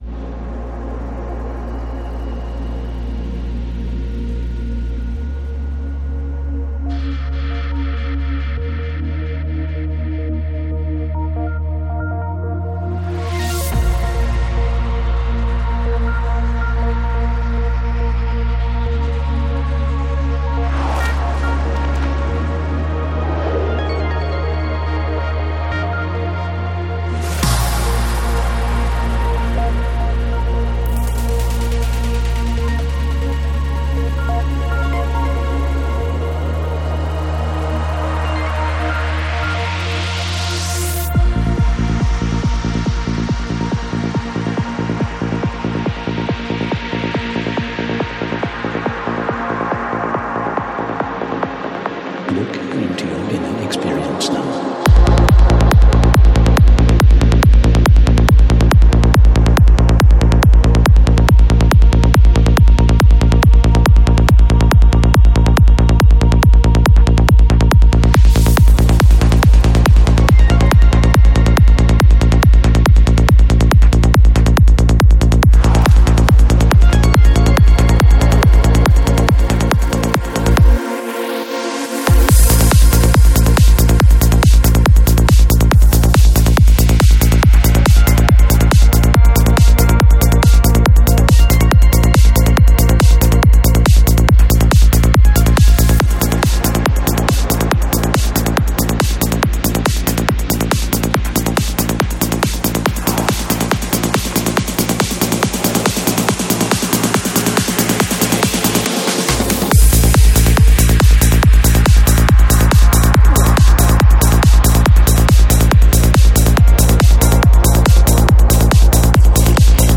Жанр: Транс
21:26 Альбом: Psy-Trance Скачать 7.09 Мб 0 0 0